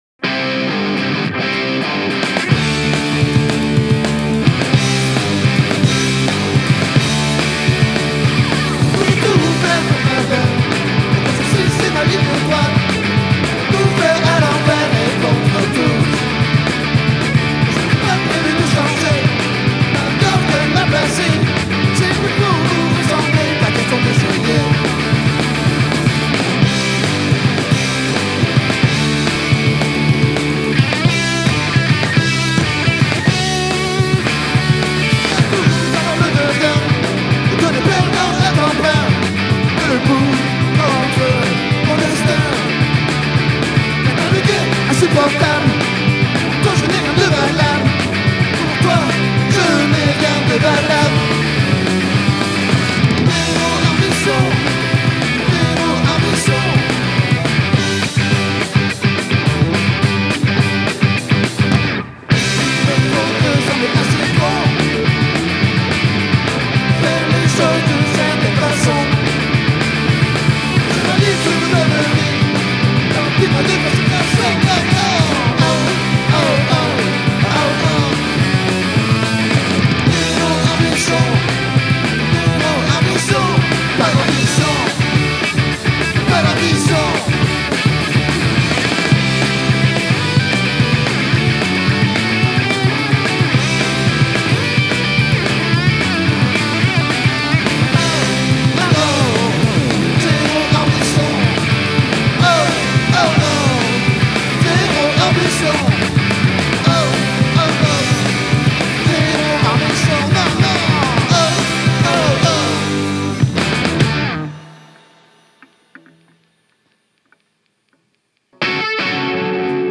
Musical rowdies from Montpelier.